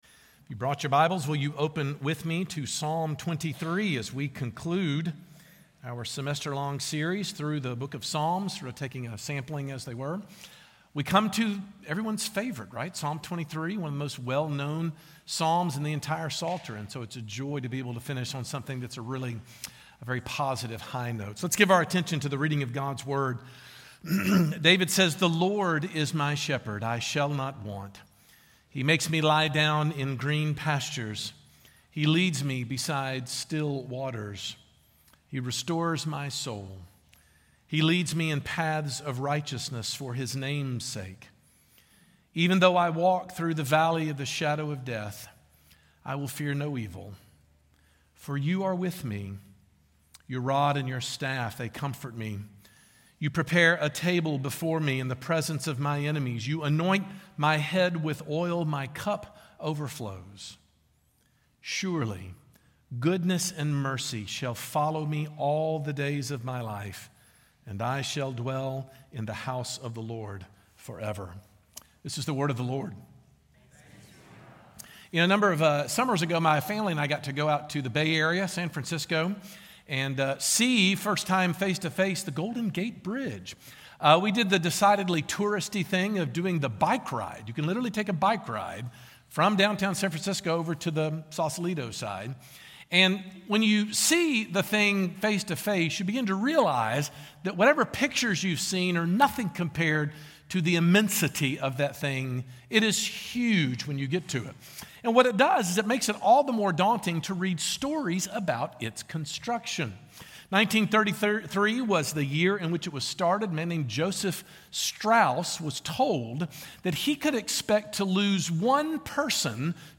Sermon Points: